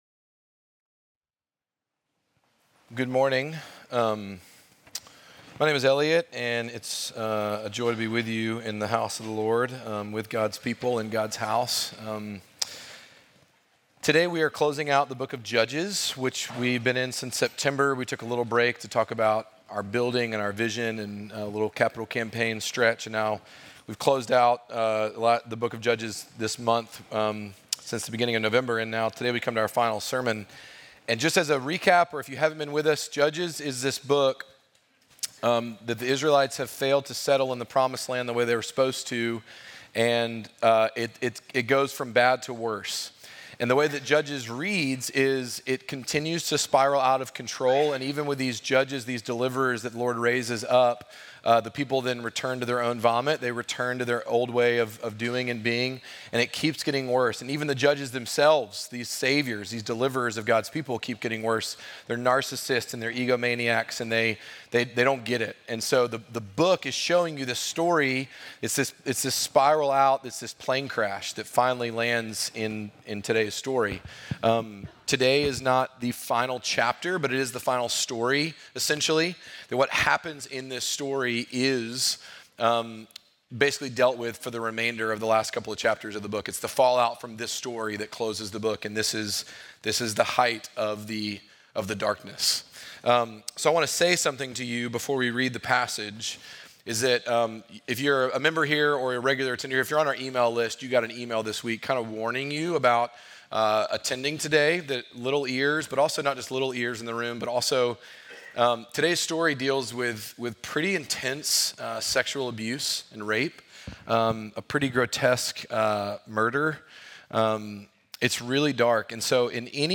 Midtown Fellowship 12 South Sermons A Woman Torn Apart Nov 24 2024 | 00:52:50 1x 00:00 / 00:52:50 Subscribe Share Apple Podcasts Spotify Overcast RSS Feed Share Link Embed